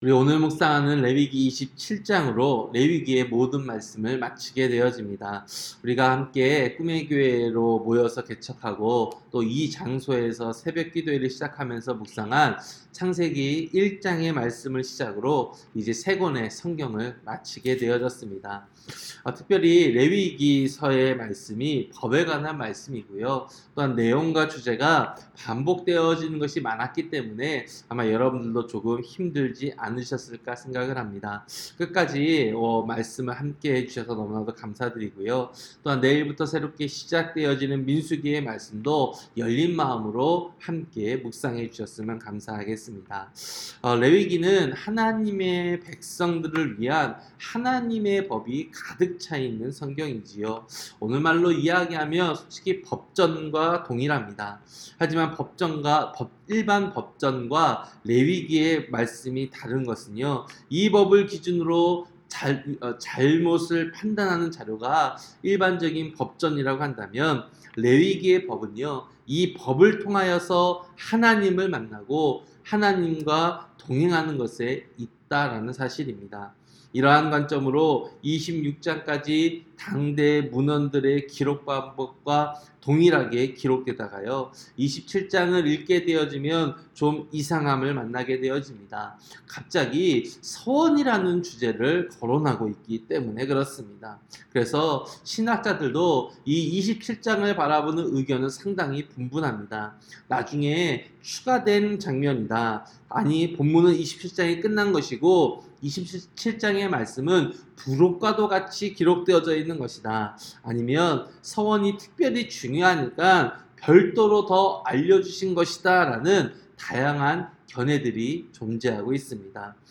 새벽설교-레위기 27장